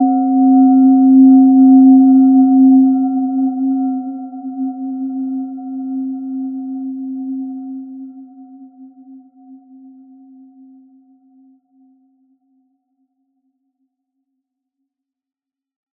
Gentle-Metallic-4-C4-mf.wav